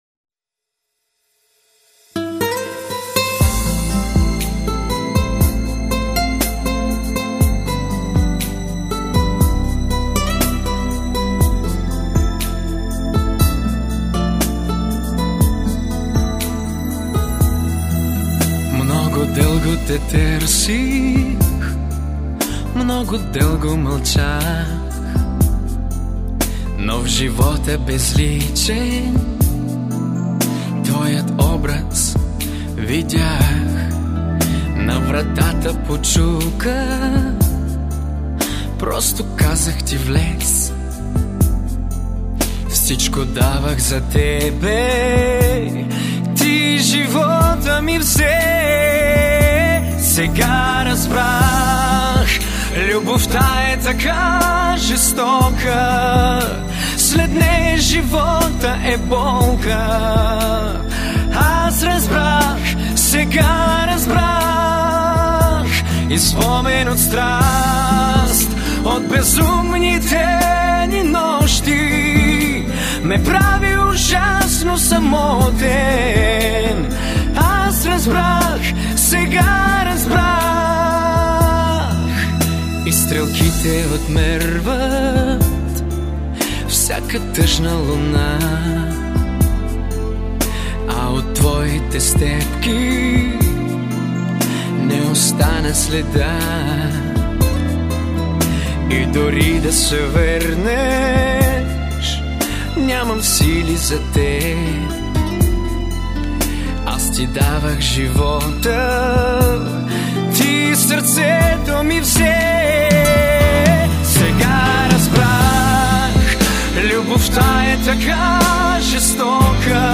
Тенор Баритон